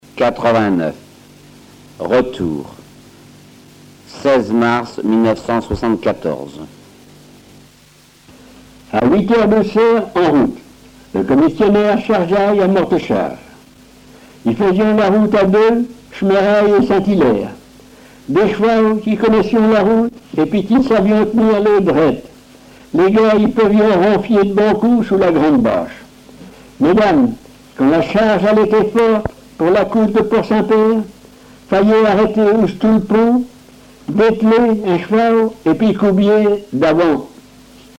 Genre récit
Récits en patois